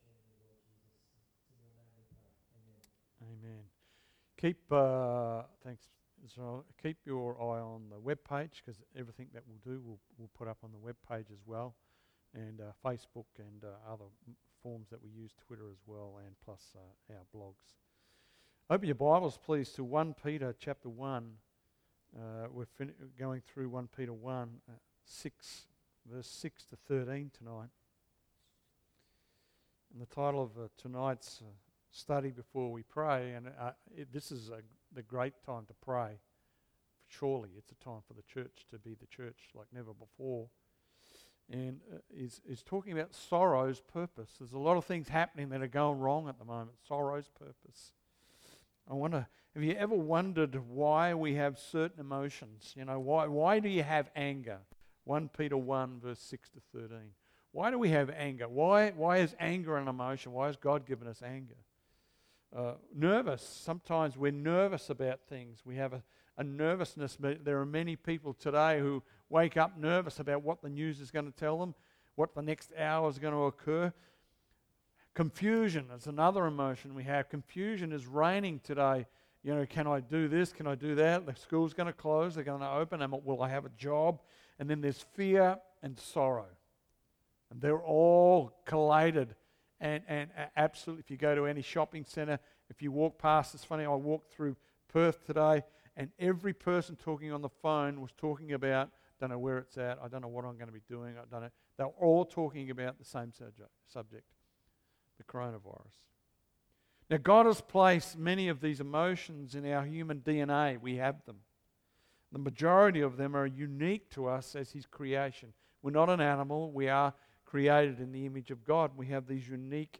Sorrow of this World – Topical Calvary Chapel Secret Harbour